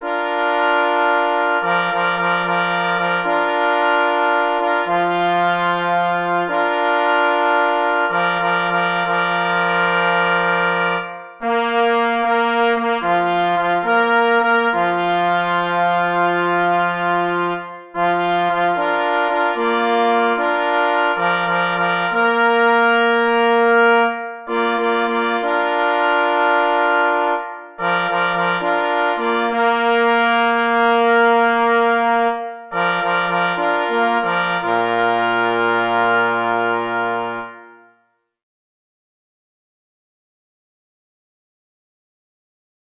Opis zasobu: hejnał na 2 plesy i parfors opracowany przez […]
E. Kruszelnicki – Hejnał Technikum Leśnego w Rogozińcu – na 2 plesy i parfors | MP3